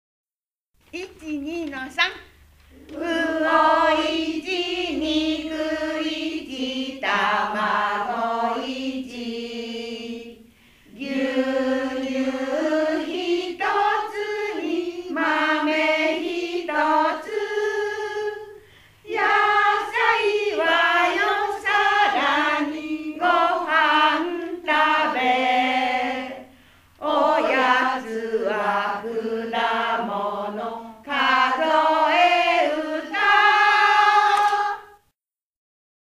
“夕焼け小焼け”のメロディーで楽しく歌って、お食事チェック